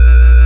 ForceBuzz1.wav